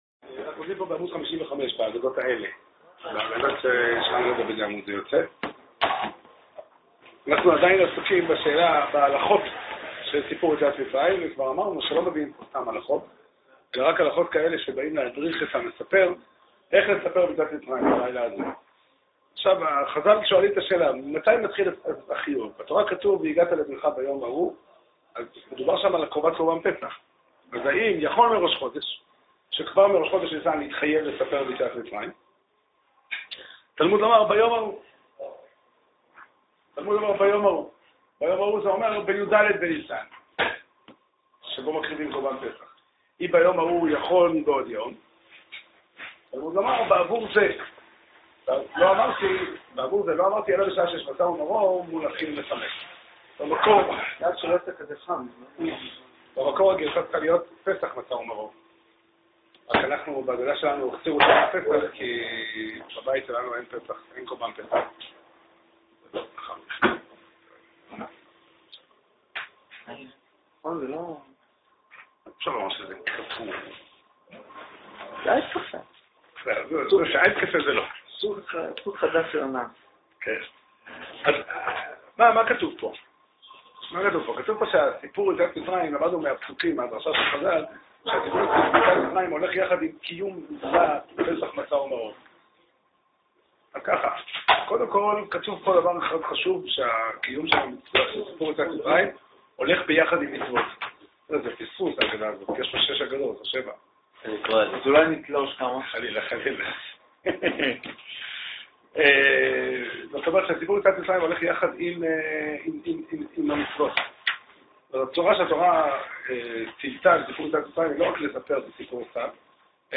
שיעור שנמסר בבית המדרש 'פתחי עולם' בתאריך כ"ח אדר ב' תשע"ד